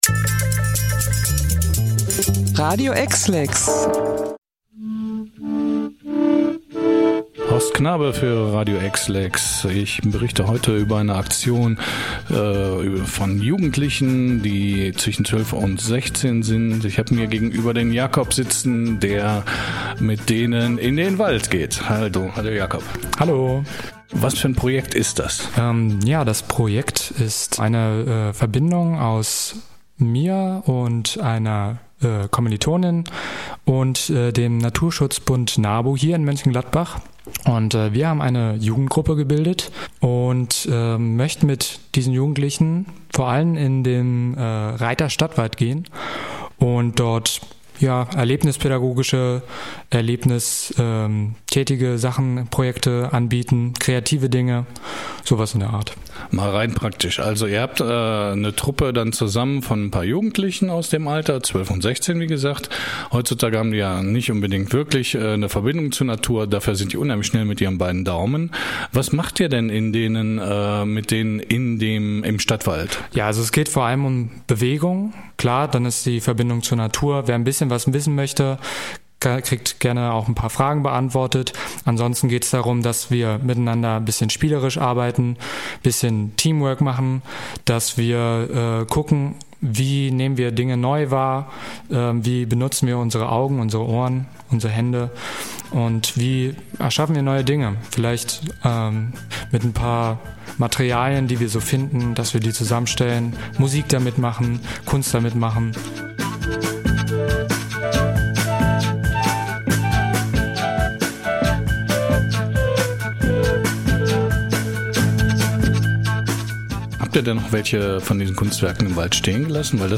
„Bäume umarmen iss nich“ – mit den Woodfellas durch den Rheydter Stadtwald
Radio-EXLEX-Interview-Woodfellas.mp3